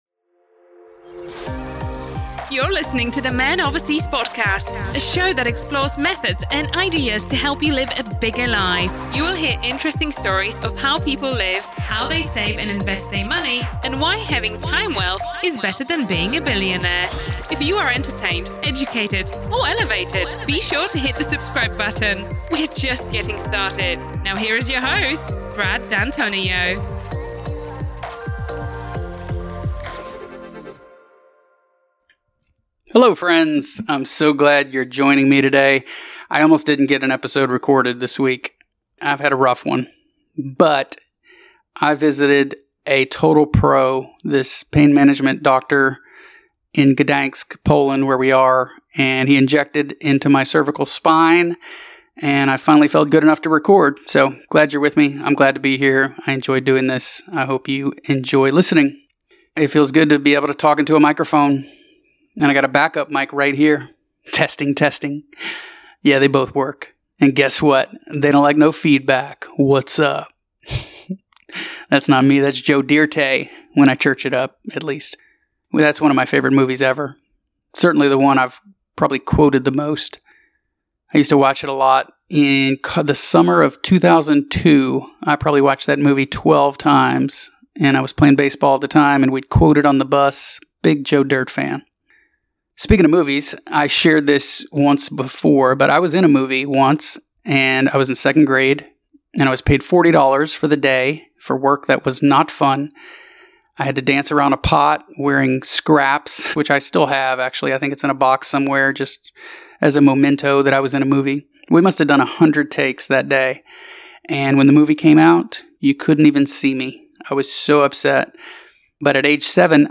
Almost didn’t get this solo episode recorded.